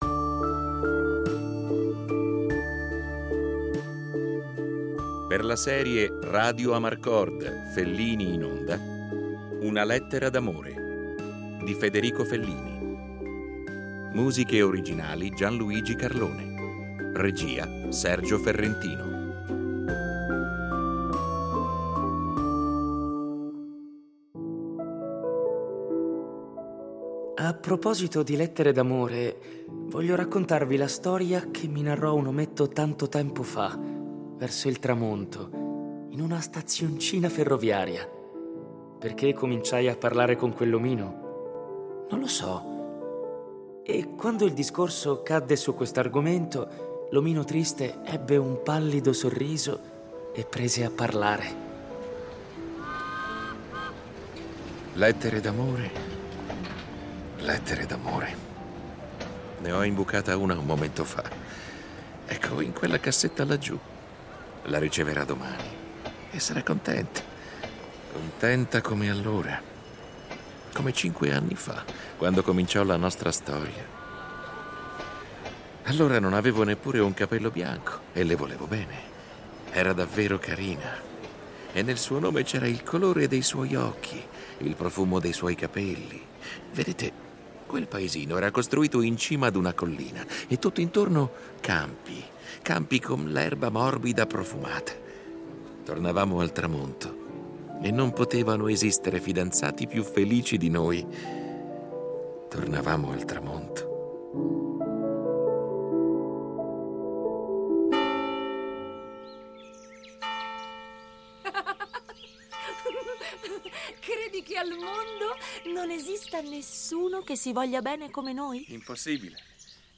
(NARRATORE)